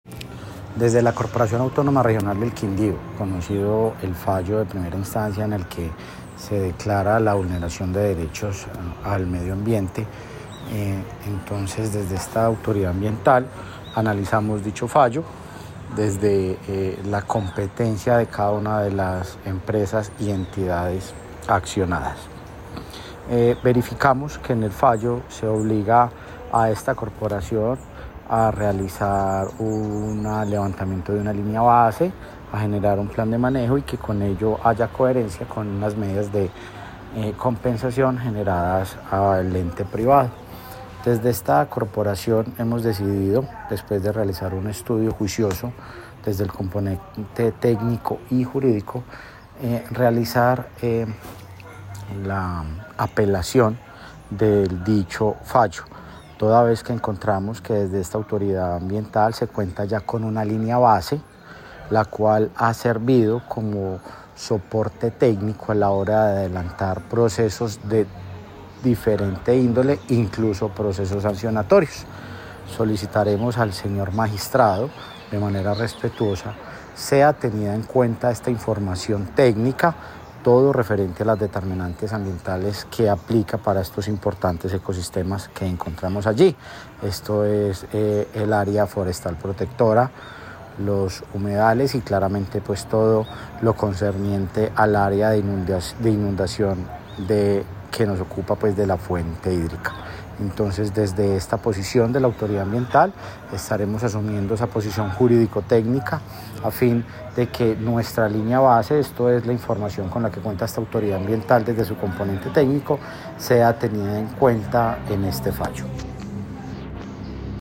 AUDIO JUAN ESTEBAN CORTÉS OROZCO- DIRECTOR GENERAL ENCARGADO DE LA CRQ